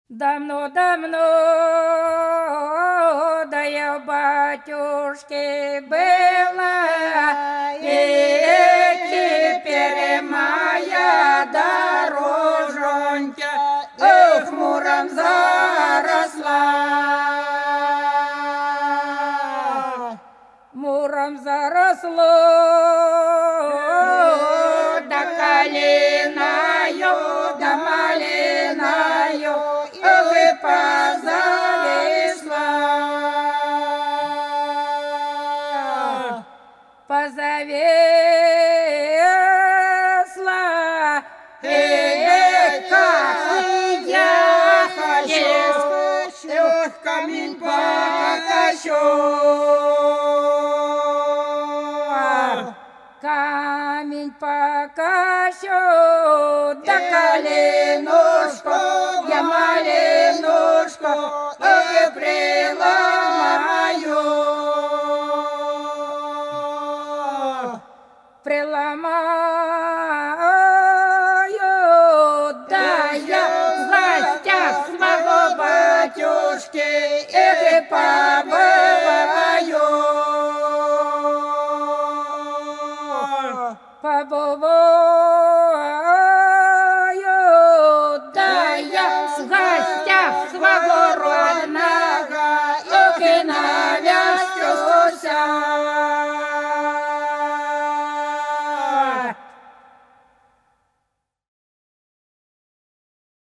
По-над садом, садом дорожка лежала Давно, давно, я у батюшки была - масленичная (с.Плёхово, Курской области)